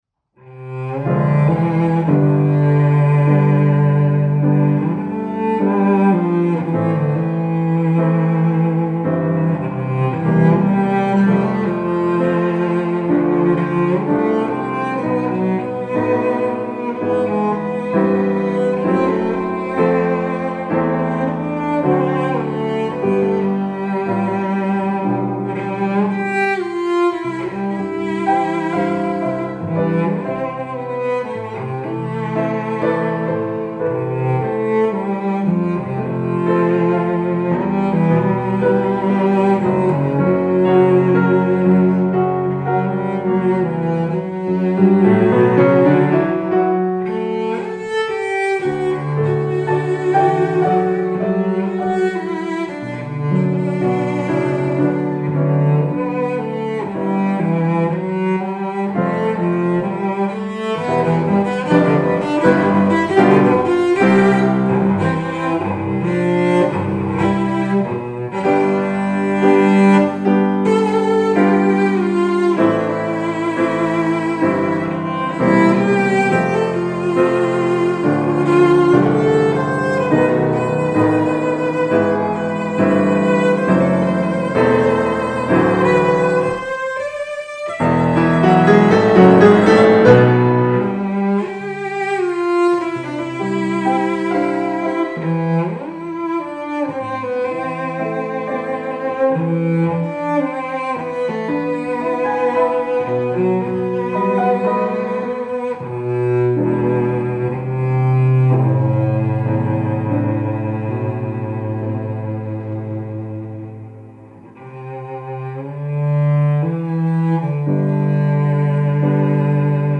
+2020 original Cloud collaboration Recording
Cello